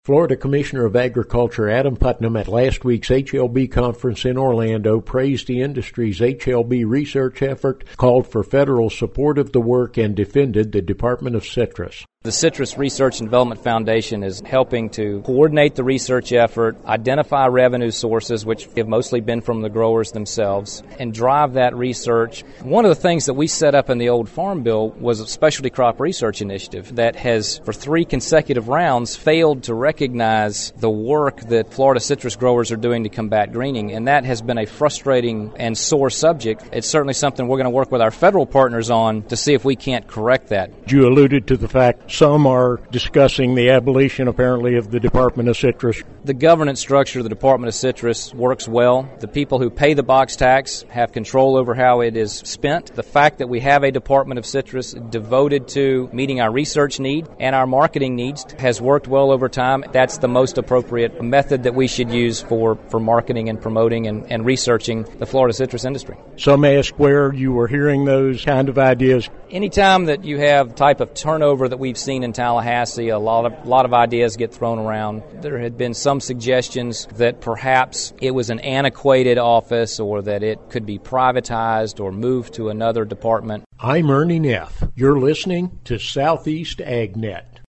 Speaking at last week’s International Research Conference on HLB in Orlando, Commissioner of Agriculture Adam Putnam praised the work of the Citrus Research and Development Foundation, called for more federal funding of HLB research and defended the Florida Department of Citrus.